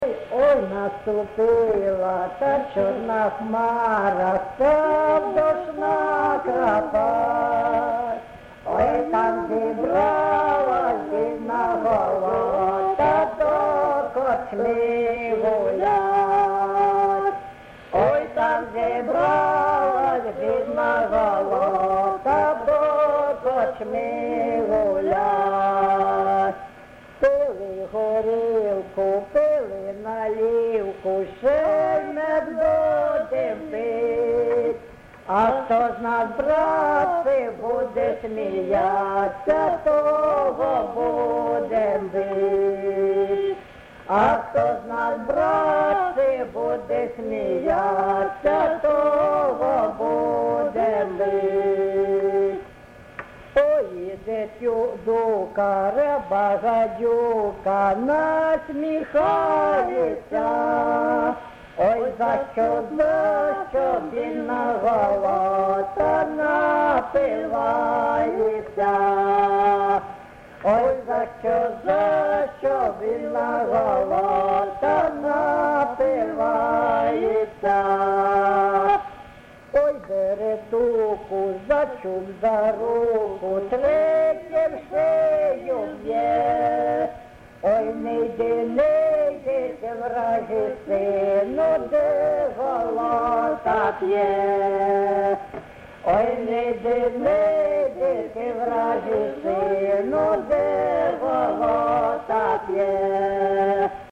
ЖанрПʼяницькі
Місце записум. Антрацит, Ровеньківський район, Луганська обл., Україна, Слобожанщина